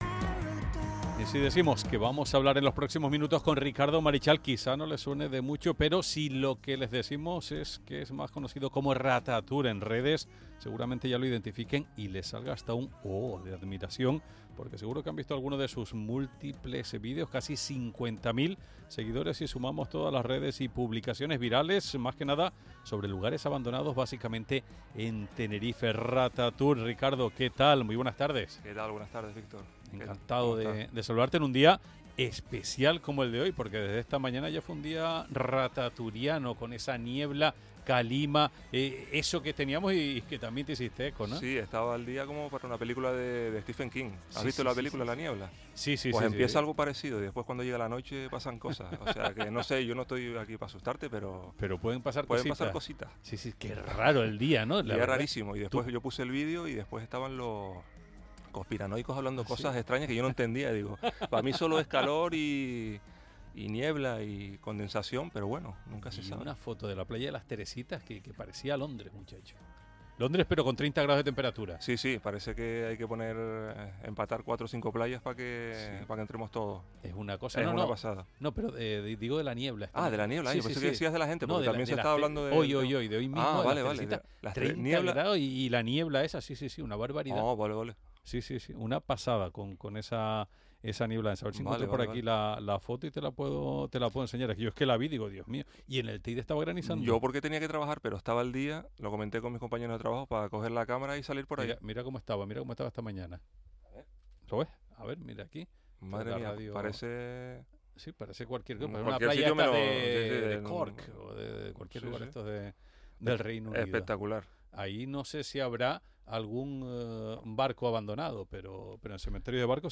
ESCUCHA AQUÍ LA ENTREVISTA A RATATOUR EN LA RADIO CANARIA DEL 11 DE AGOSTO DE 2025